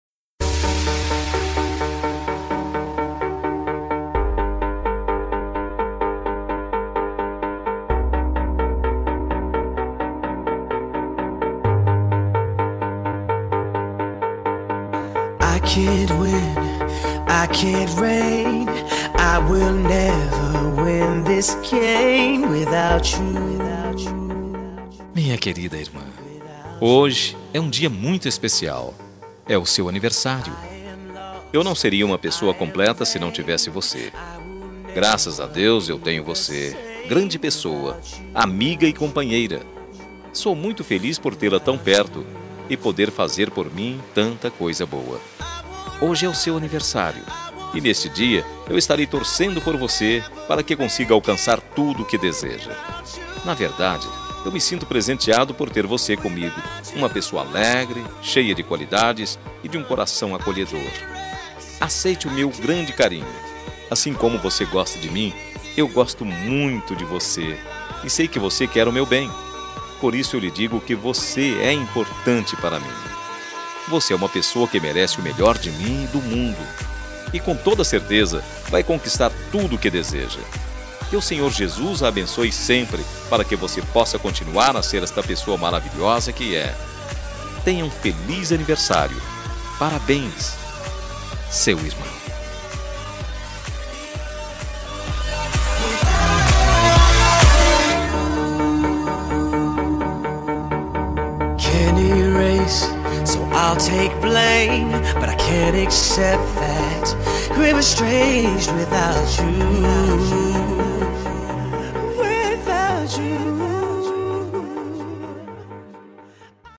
Telemensagem de Aniversário de Irmã – Voz Masculina – Cód: 202228